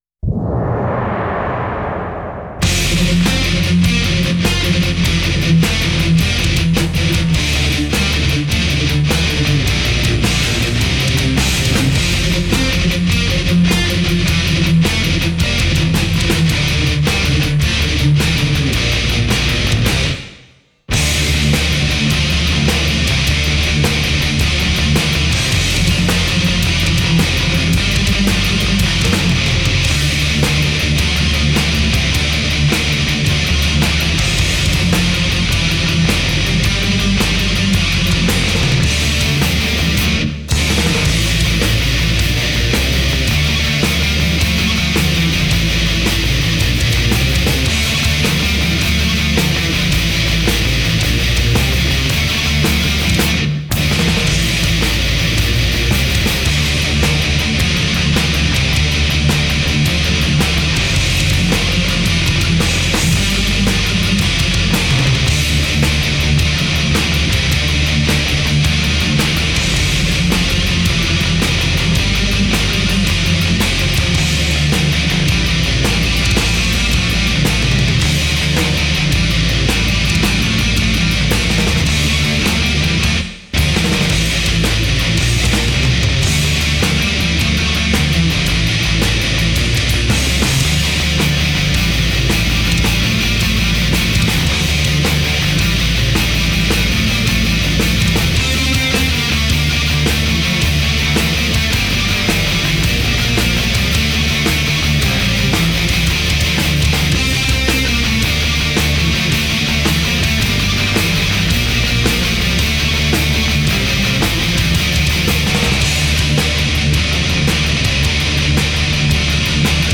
Для любителей инструментала )